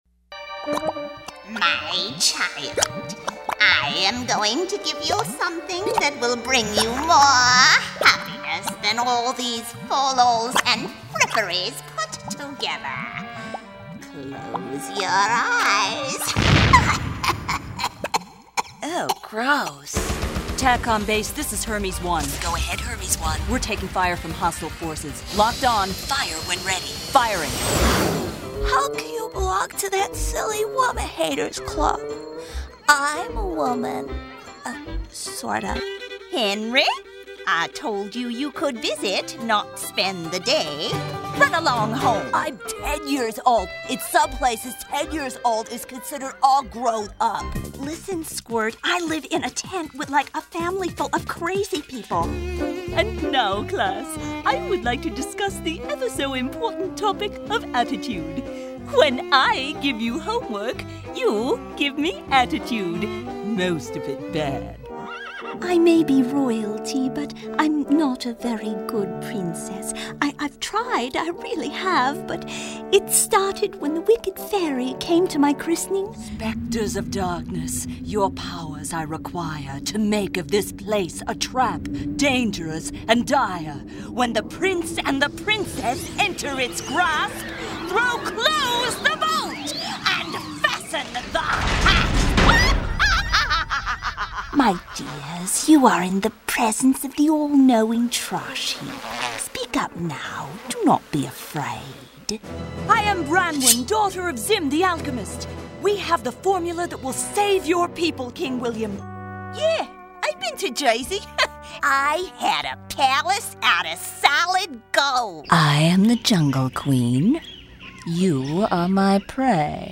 Character Voice Overs / Character Voiceover Actor Demos
Female voice over talent